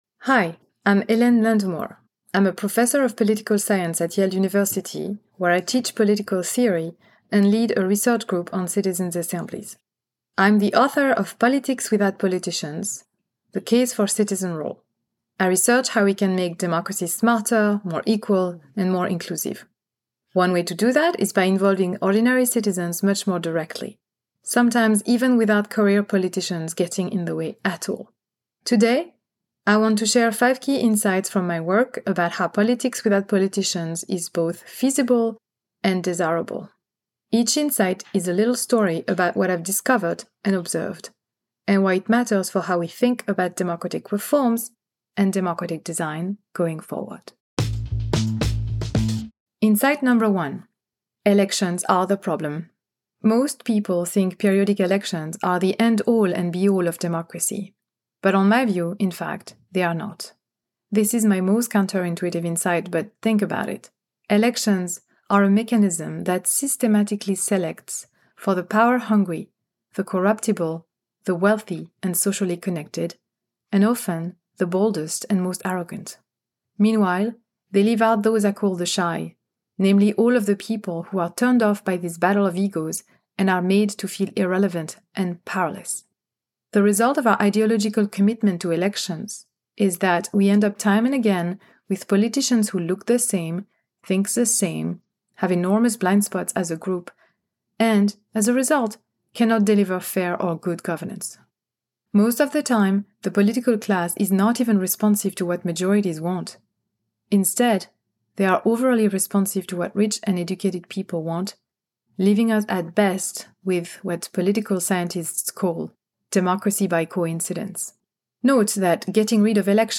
Book Bites Politics & Economics